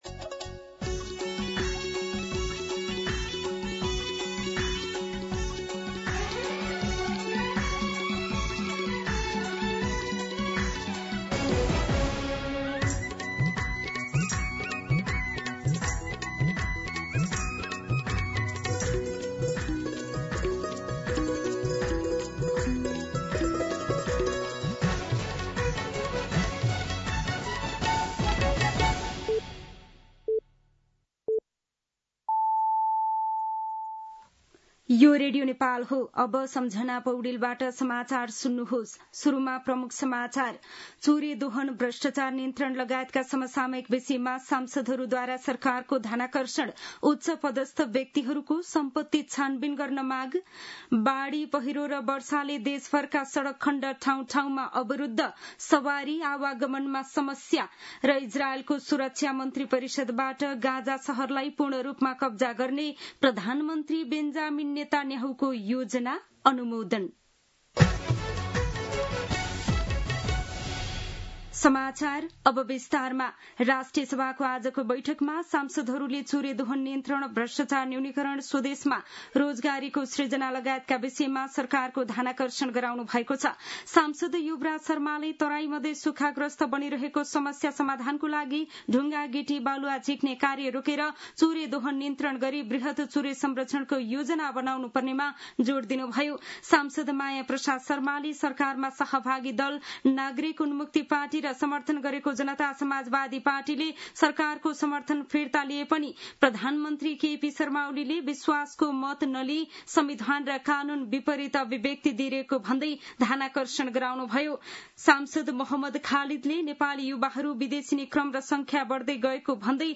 दिउँसो ३ बजेको नेपाली समाचार : २३ साउन , २०८२
3-pm-Nepali-News-.mp3